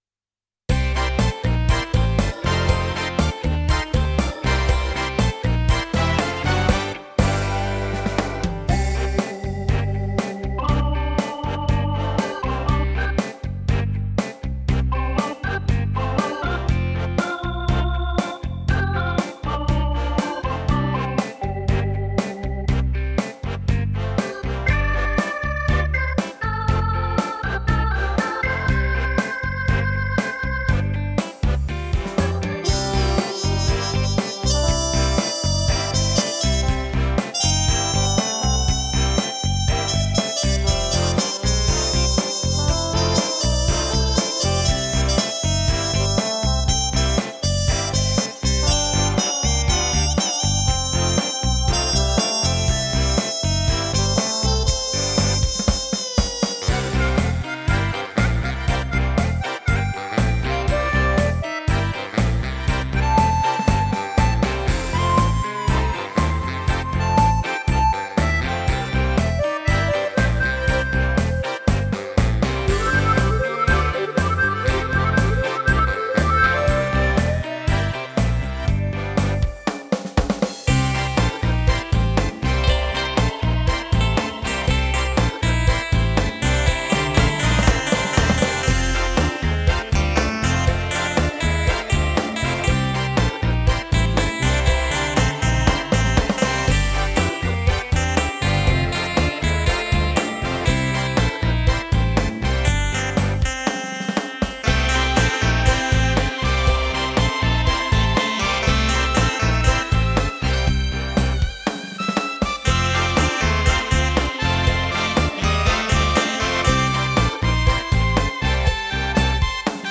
seul fait les 3/4 du "boulot" 3 accord Do Fa Sol, on prends un sac à dos on y ajoutes quelques
pour le style changement de 3voix mixing tempo 120 et picétou
on va pas chipoter pour quelques kilos) - Harmonica - TrumpetShake - BaritoneSax - Trombonne -